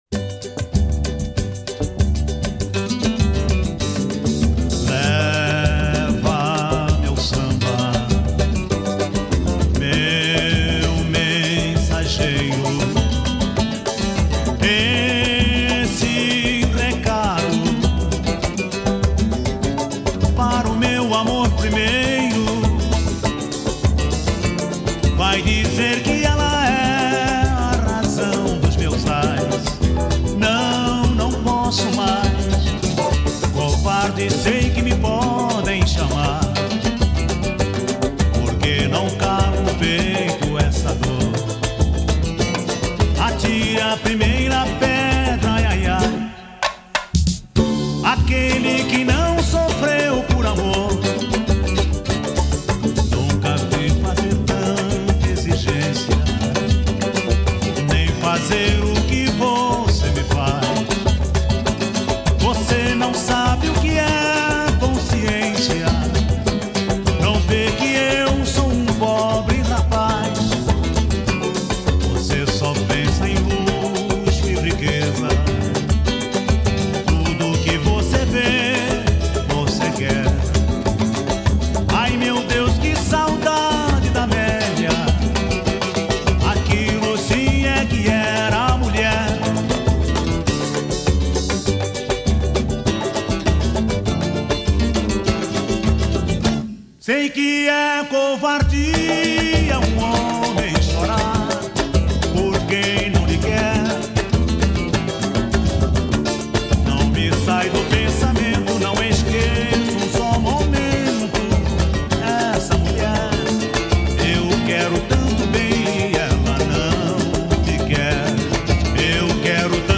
sambas
curta o gostoso ritimo nacional